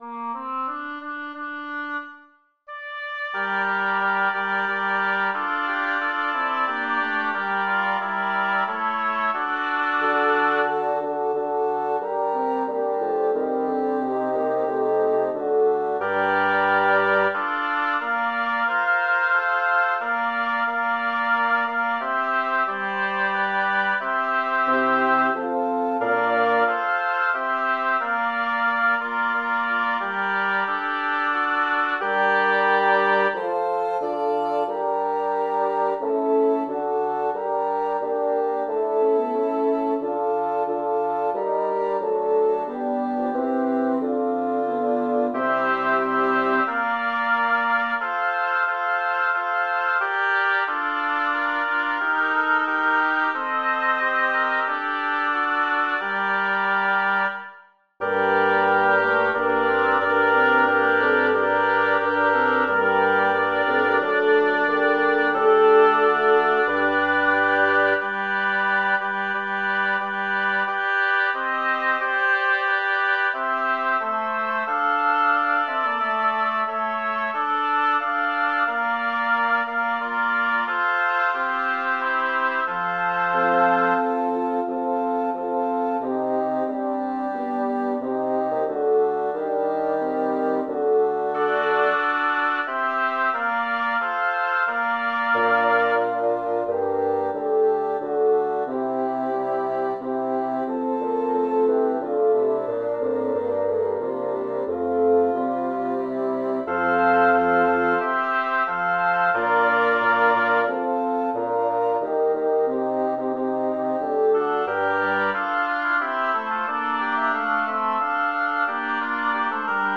Title: Magnificat Composer: Vincenzo Bellavere Lyricist: Number of voices: 8vv Voicings: SSAT.SATB or SATT.ATBB Genre: Sacred, Motet
Language: Latin Instruments: A cappella